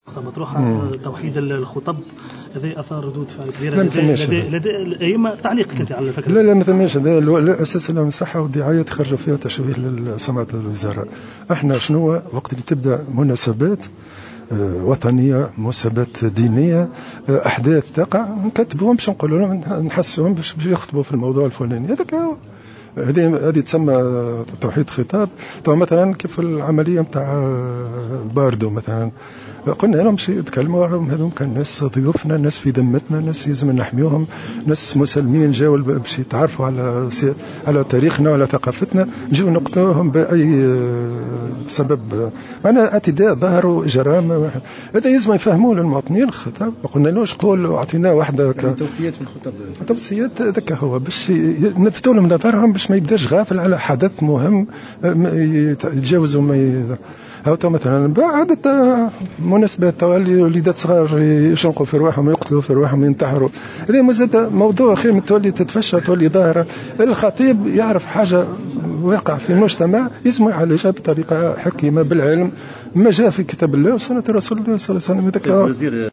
Ecoutez sa déclaration